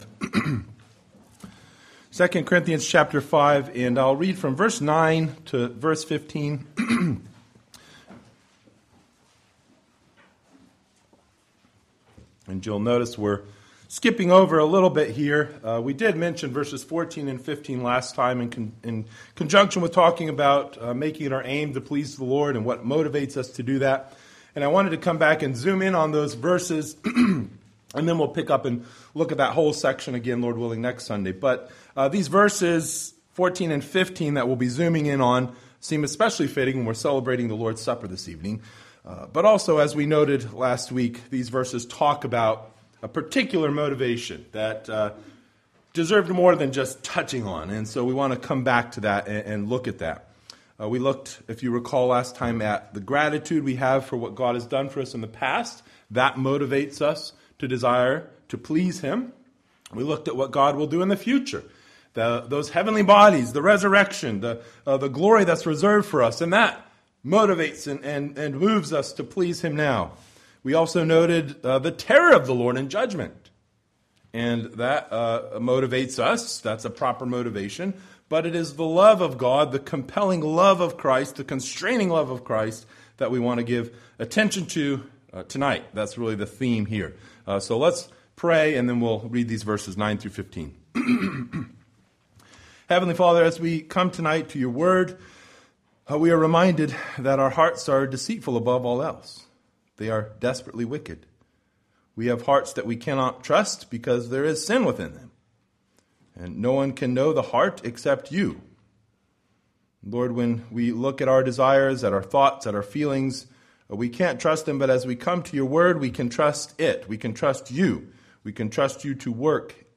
Passage: II Corinthians 5:9-15 Service Type: Sunday Evening